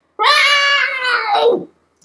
cat.wav